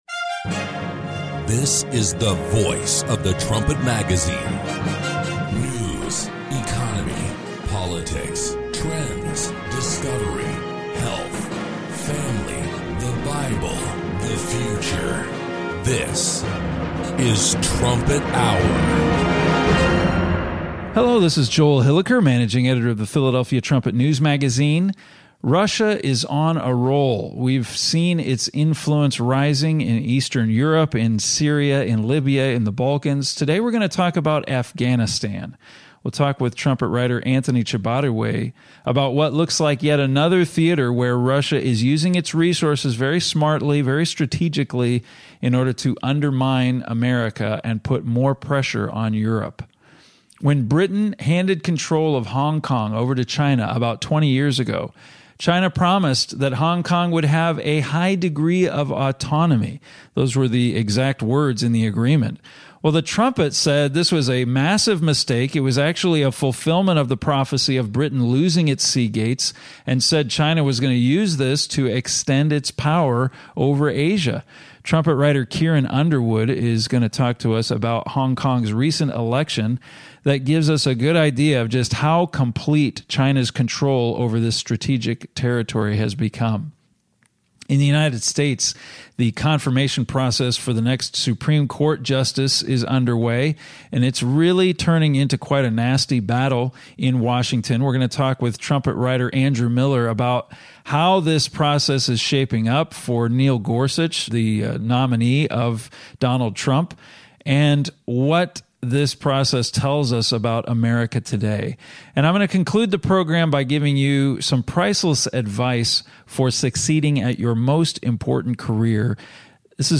Join the discussion as Trumpet staff members compare recent news to Bible prophecy.